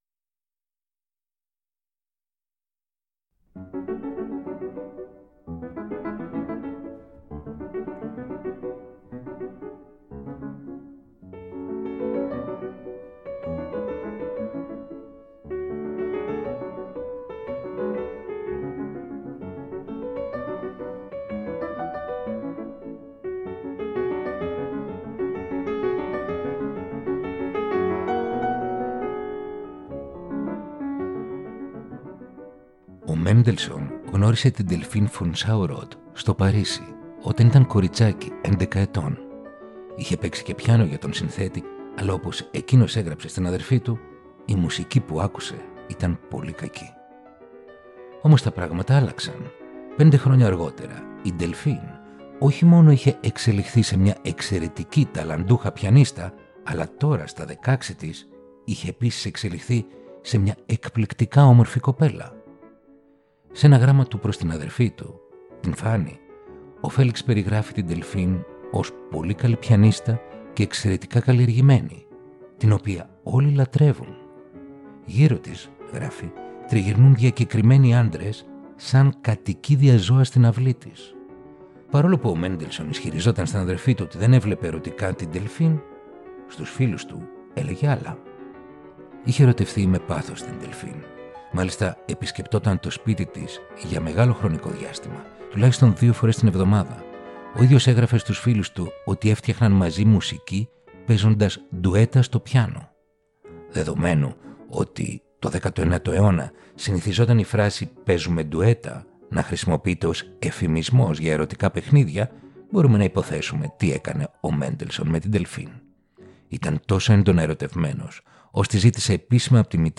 Ρομαντικά κοντσέρτα για πιάνο – Επεισόδιο 10ο